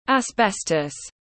A-mi-ăng tiếng anh gọi là asbestos, phiên âm tiếng anh đọc là /æzˈbestəs/.
Asbestos /æzˈbestəs/